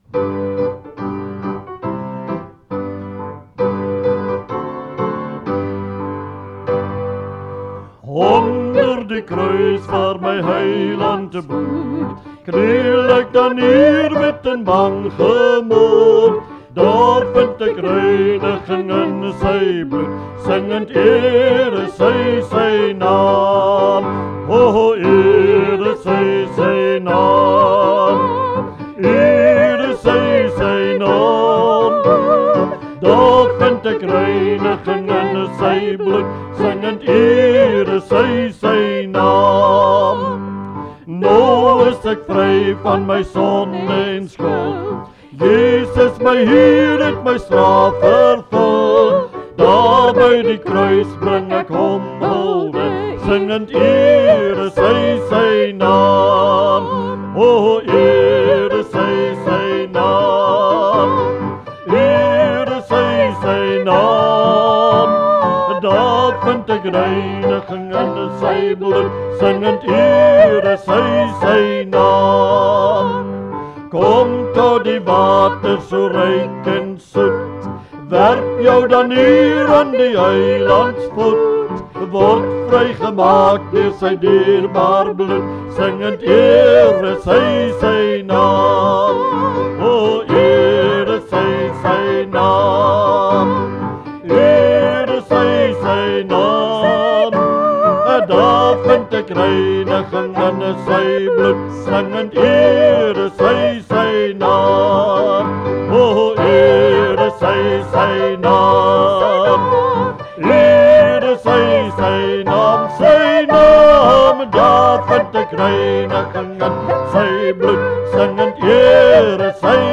Liedere - Begeleiding en samesang
Dit is geensins professionele opnames nie.